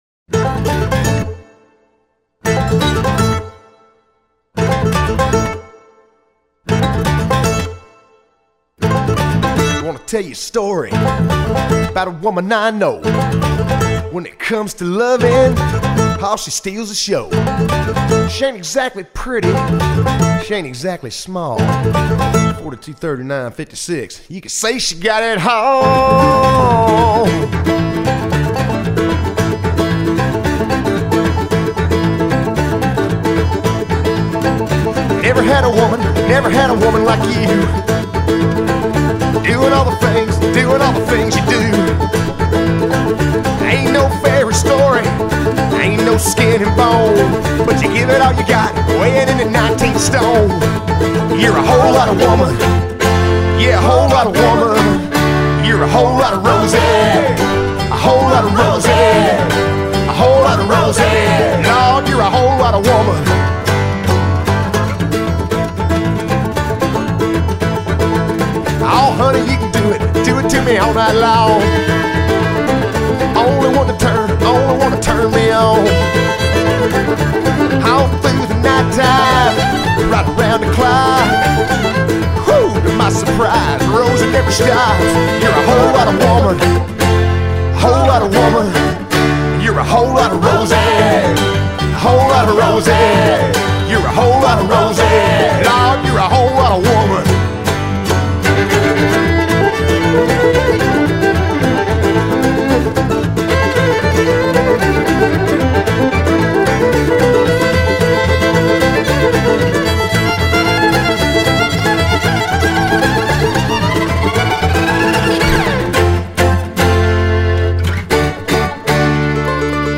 Hard Rock Панк Рок Кантри